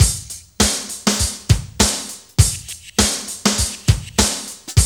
BREAKBEAT4-2.wav